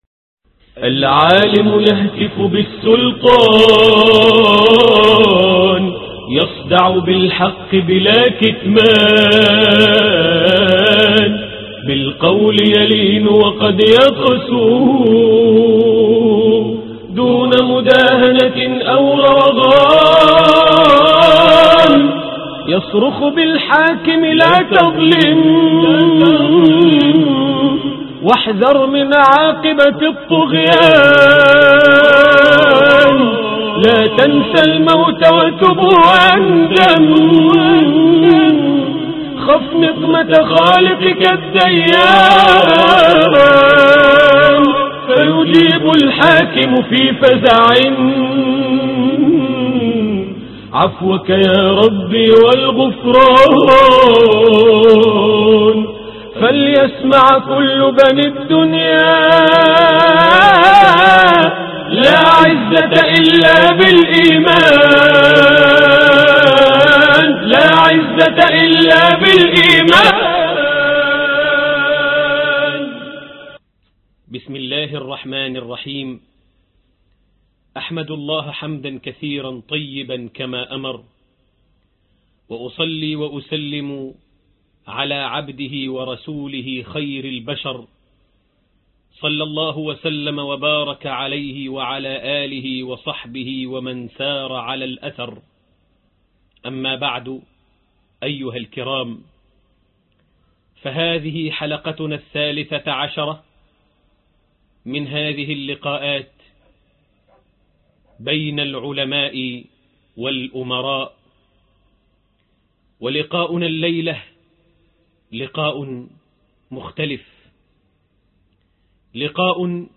الدرس 13 (قوة المؤمن) بين العلماء والأمراء